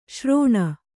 ♪ śrōṇa